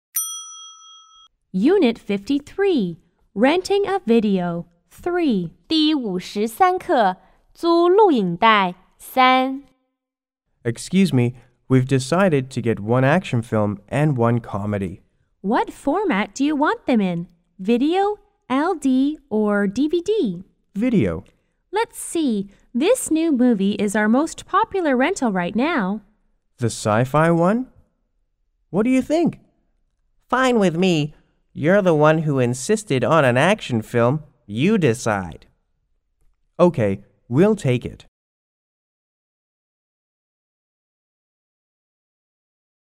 C= Customer 1 S= Salesperson